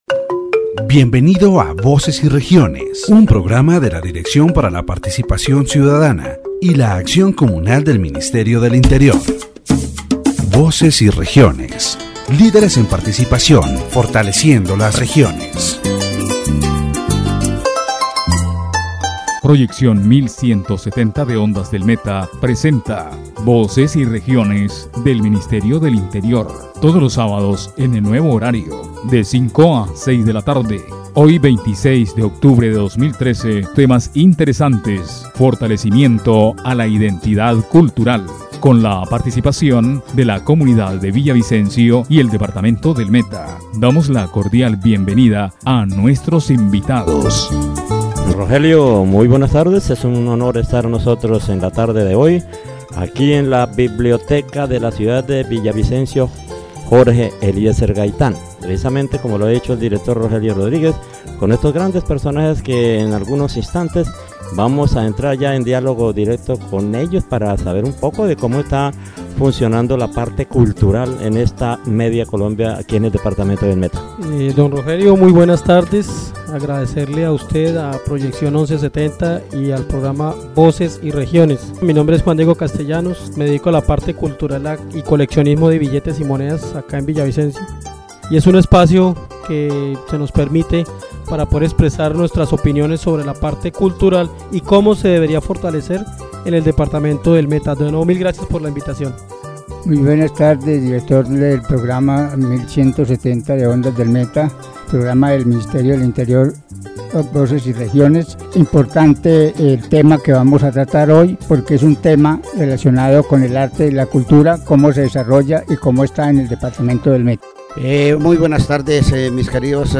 The radio program "Voices and Regions" delves into a deep discussion about culture and politics in Colombia, particularly in the Meta department. Participants discuss the importance of preserving the Llanos folklore, the need for more government support for arts and culture, and the significance of informed citizen participation in electoral processes.